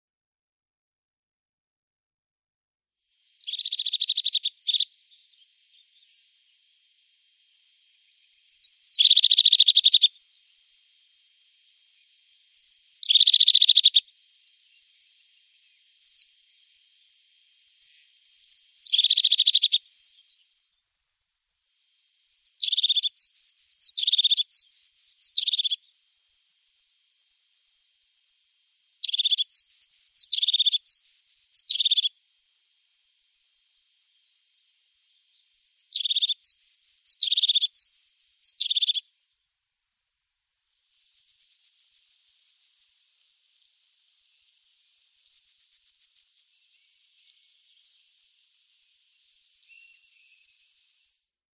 Haubenmeise
Ihren anmutigen Ruf, ein trillerndes "Zizi g�rrr - Zizi g�rrr" h�rt man zu jeder Jahreszeit.